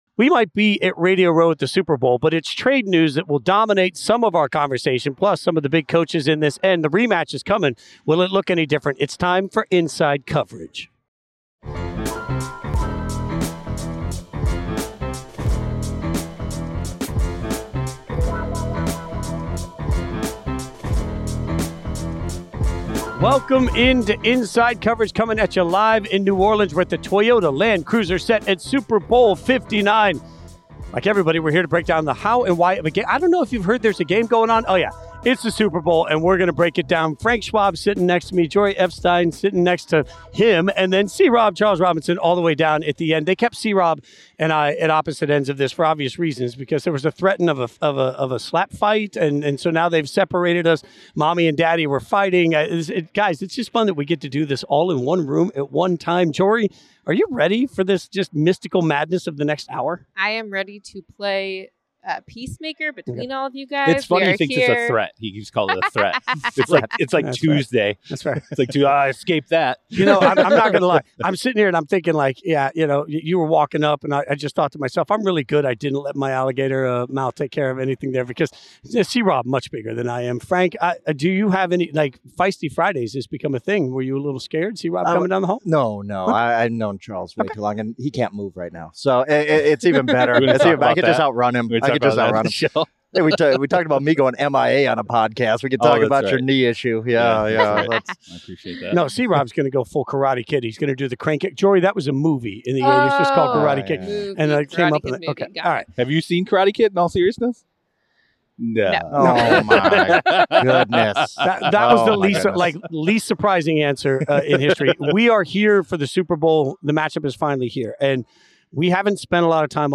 live from Radio Row in New Orleans